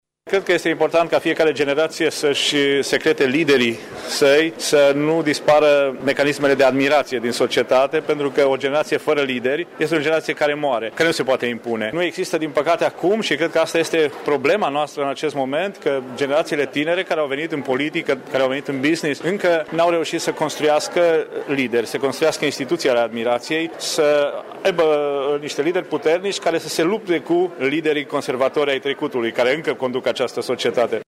Asta susţine autorul volumului „Triburile. Patologii ale politicii româneşti, de la Revolutie la Generaţia Facebook” lansată astăzi la Bookfest Târgu-Mureş.
Autorul a spus că societatea românească se află într-o criză deoarece mecanismele de admiraţie au dispărut pe fondul absenţei unor lideri credibili: